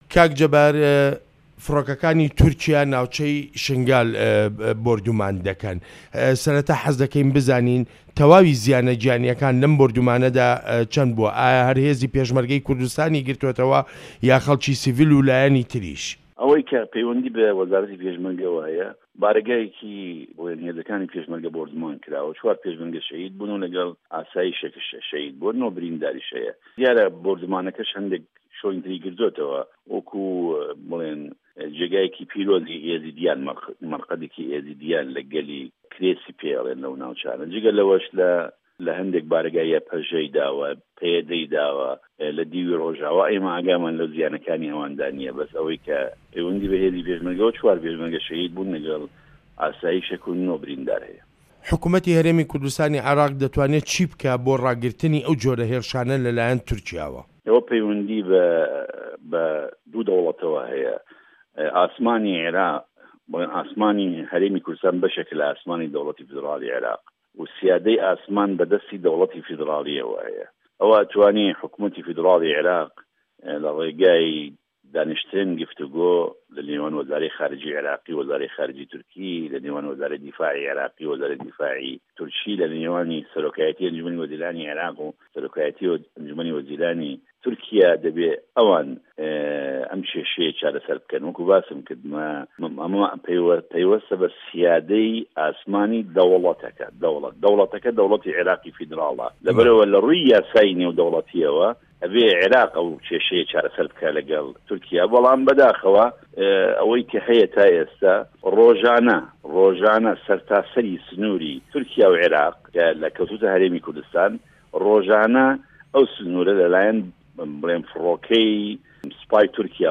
وتووێژ لەگەڵ جەبار یاوەر